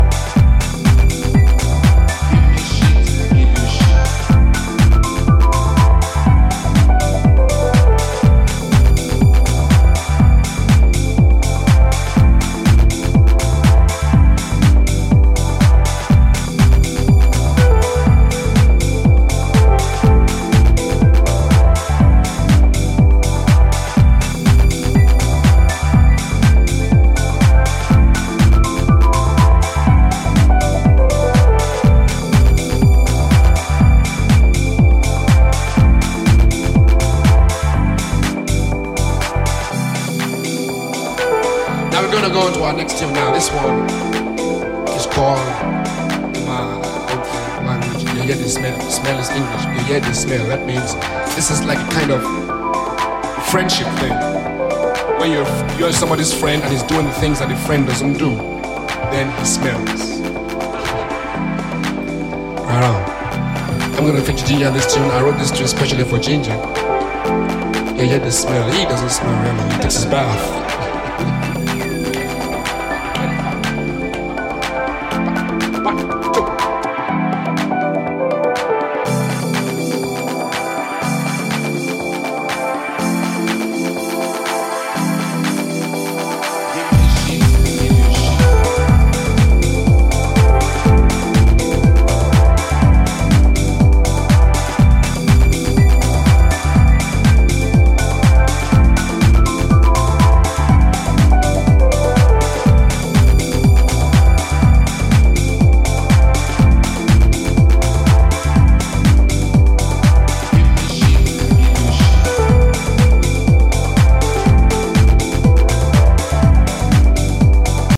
deep instrumental house with an elegant piano solo
rich funk house with a roaring organ